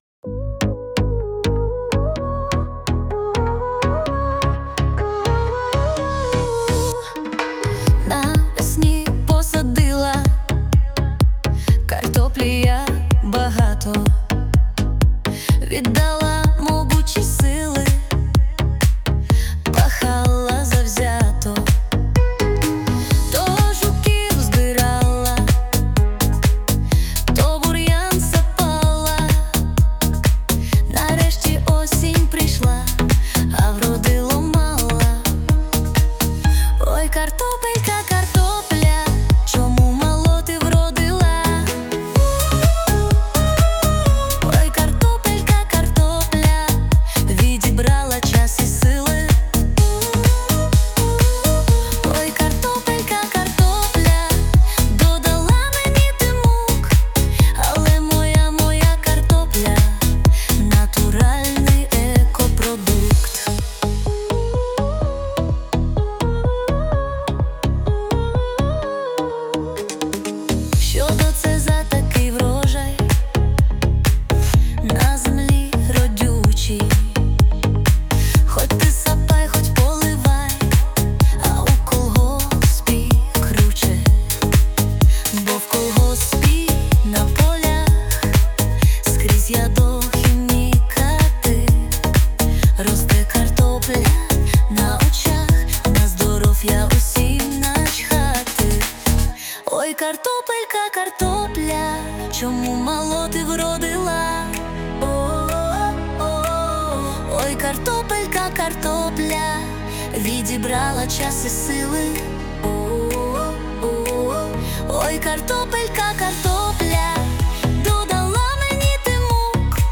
ТИП: Пісня
СТИЛЬОВІ ЖАНРИ: Гумористичний